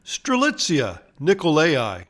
Pronounciation:
Stre-LIT-see-a ni-KO-la-i